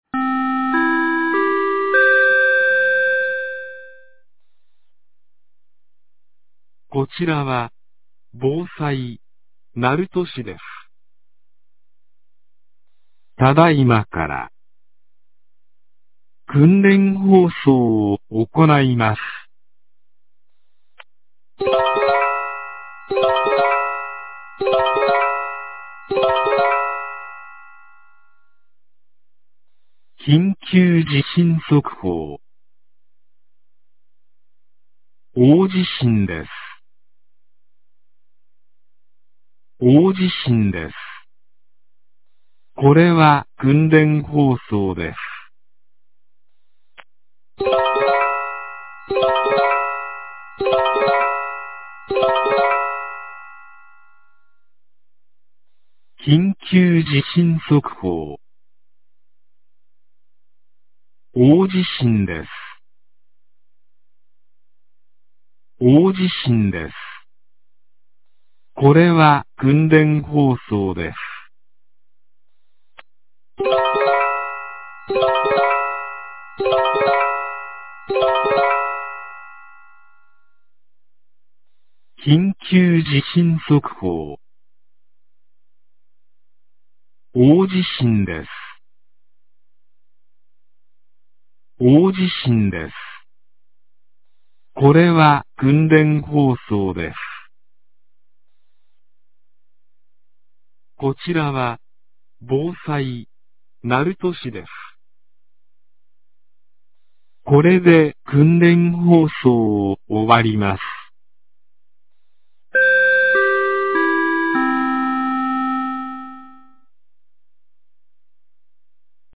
2025年06月18日 10時02分に、鳴門市より全地区へ放送がありました。
放送音声 カテゴリー: 防災行政無線放送